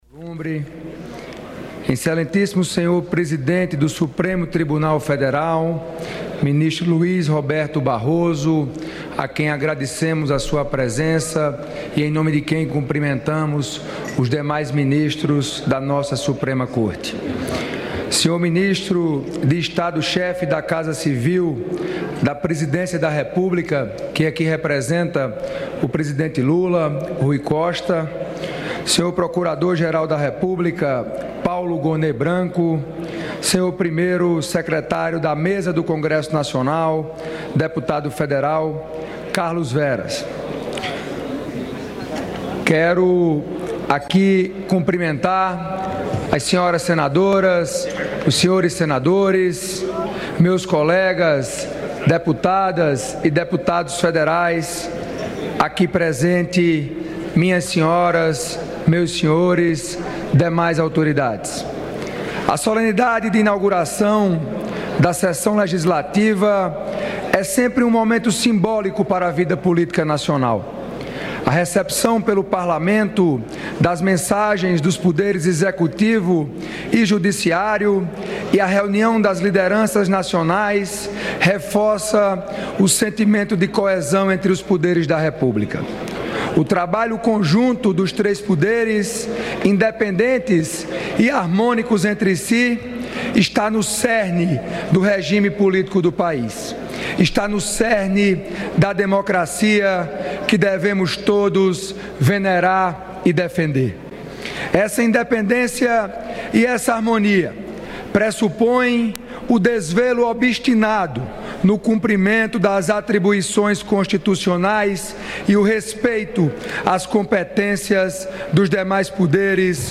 Abertura do Ano Legislativo
Pronunciamento do presidente da Câmara dos Deputados
O presidente da Câmara dos Deputados, Hugo Motta (Republicanos-PB), ressaltou, em seu discurso de abertura do Ano Legislativo, a harmonia entre as duas Casas do Congresso Nacional na construção de uma pauta de votações de interesse nacional. Ele também enfatizou que a política deve ser pautada pelo diálogo e pelo respeito à Constituição.